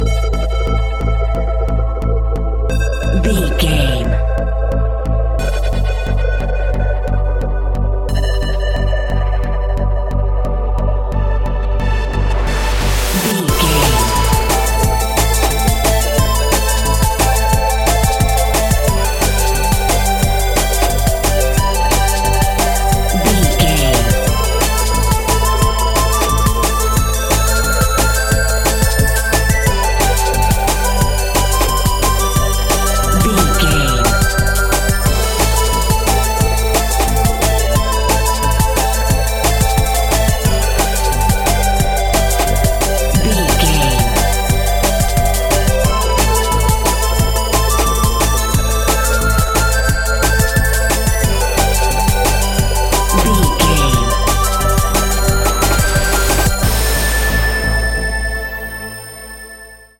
Aeolian/Minor
Fast
aggressive
dark
driving
energetic
groovy
drum machine
synthesiser
sub bass
synth leads
synth bass